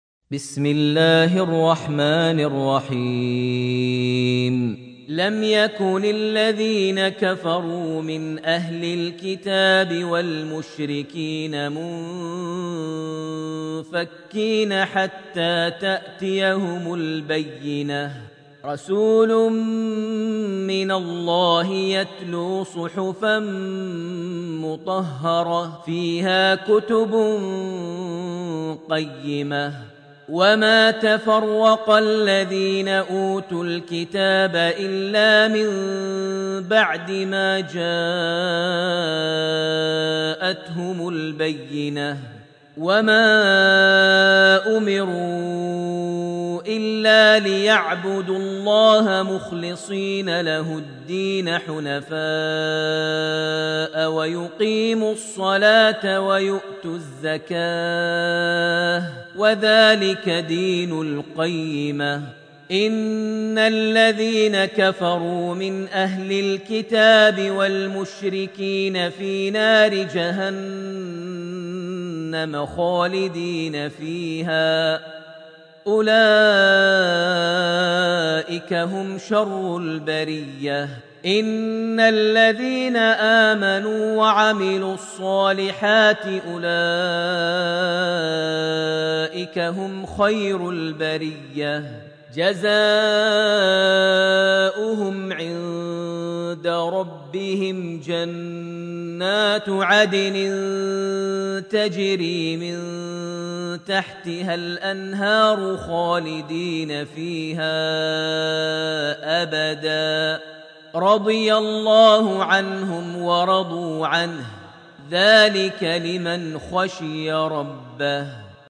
Surat Al-Binah > Almushaf > Mushaf - Maher Almuaiqly Recitations